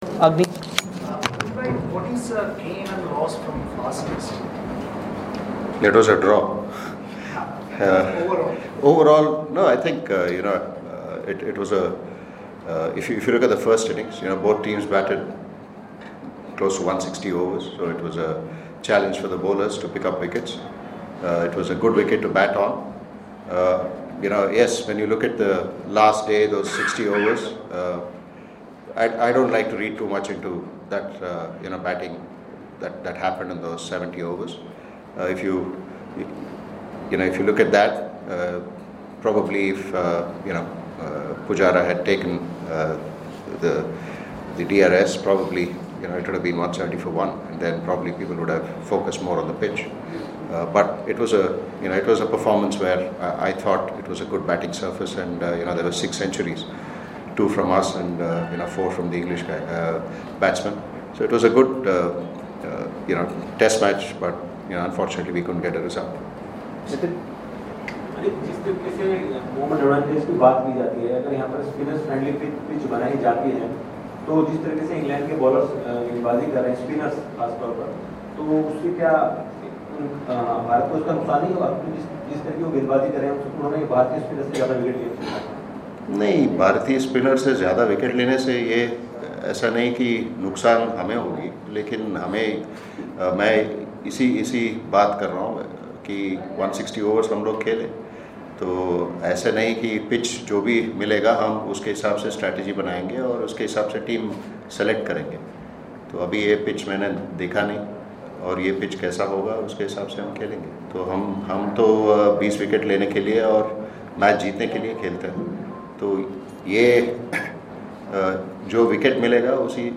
LISTEN: Team India coach speaks ahead of second Test against England.